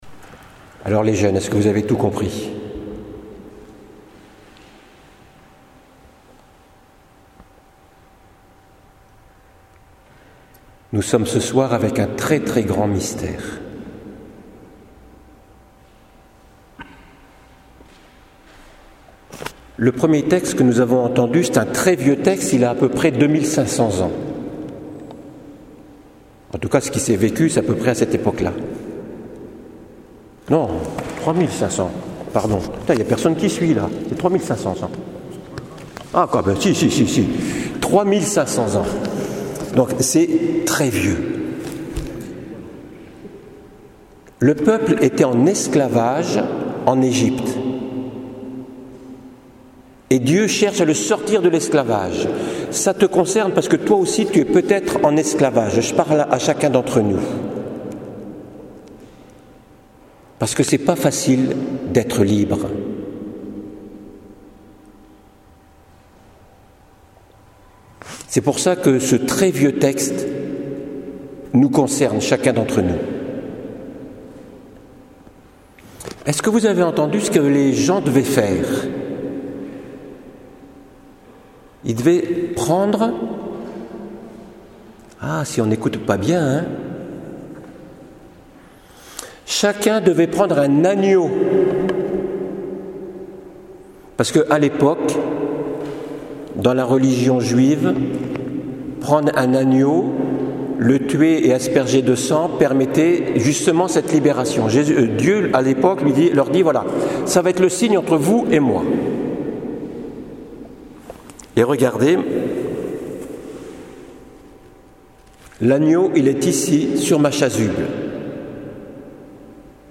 une homélie audio à l’occasion du jeudi saint avec quelques collégiens peu habitués à ce style de célébration: => jeudi saint une homélie audio p our la résurrection : => réactions face à la résurrection une homélie écrite, ci dessous donnée en ce jour à la chapelle du Berceau.
jeudi-saint.mp3